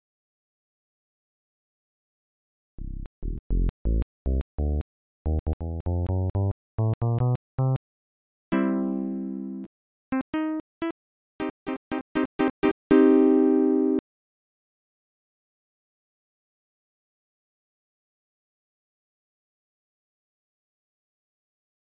This is a 2 times 10 sine/exponent block sound generator algorithm made with bwise, maxima, my own sound program and some tcl helper routines.
The resulting sound can be heared in a short musical tryout here: